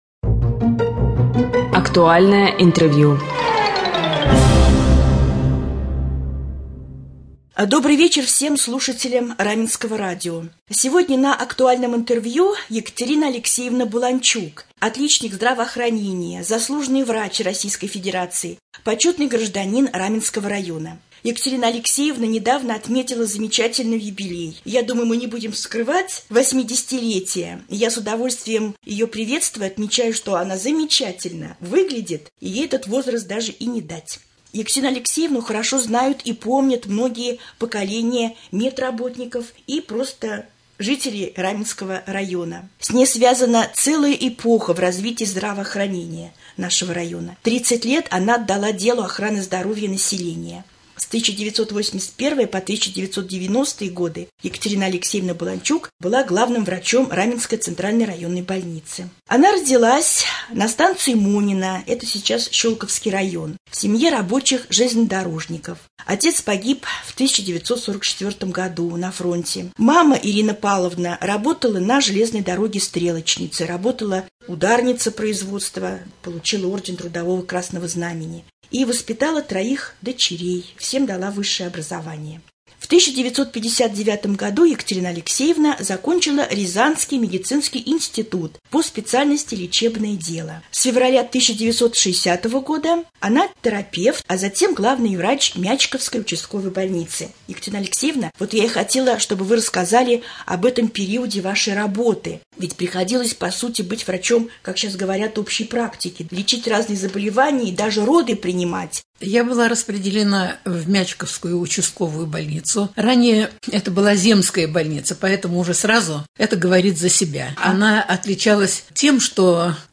Гость студии заслуженный отличник здравоохранения